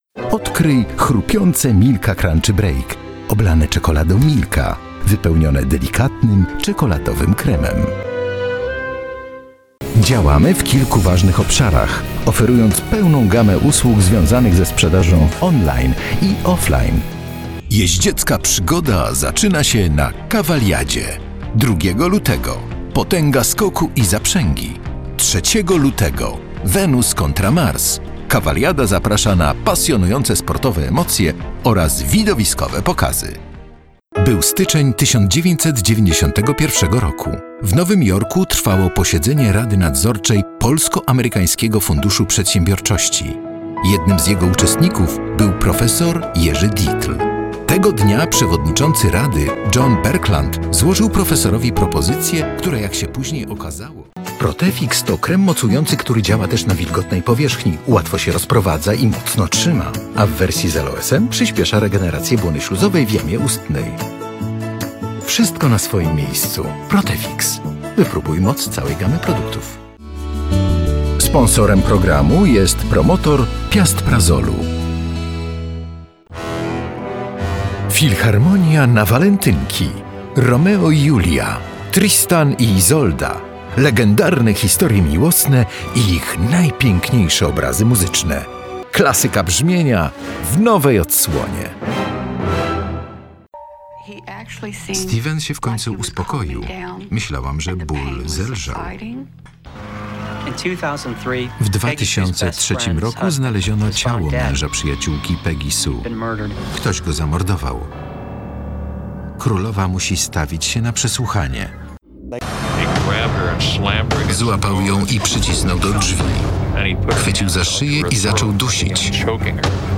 Male 50 lat +
Narracja
Demo lektorskie